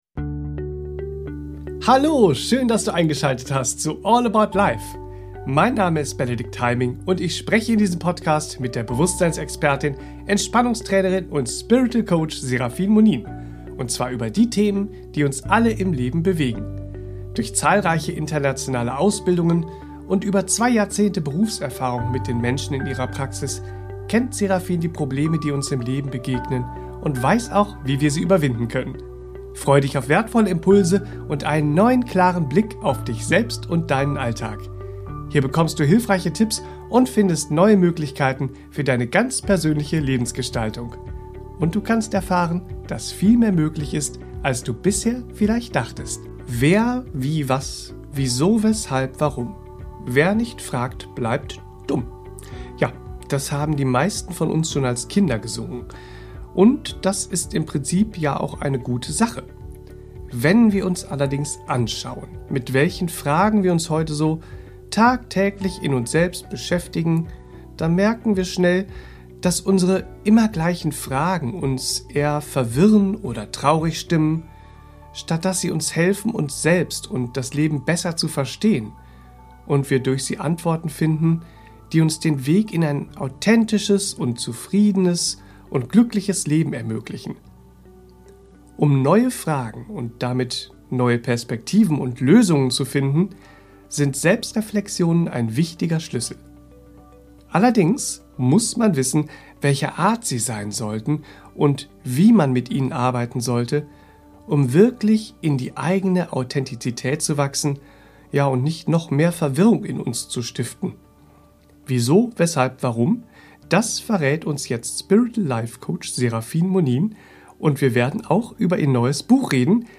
In dieser Podcast-Folge bekommst du hilfreiche Tipps für deine Selbstfindung und Persönlichkeitsentwicklung an die Hand und erfährst, wie gute Selbstreflexionen dir auf deinem Weg zu dir selbst helfen können. Im Gespräch